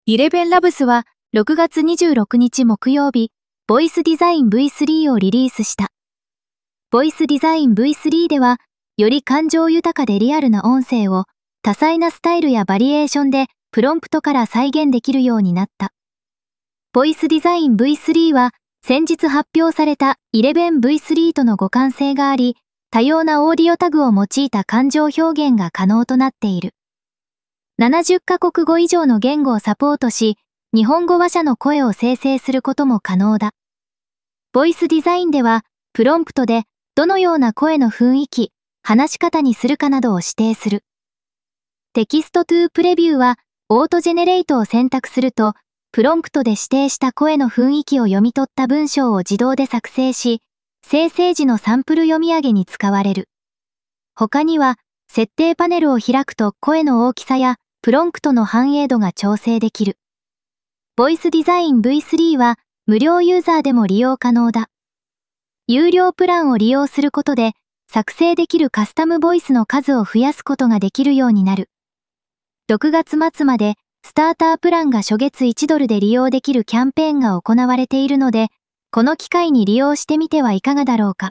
(本記事の読み上げ音声も Voice Design v3 にて作成)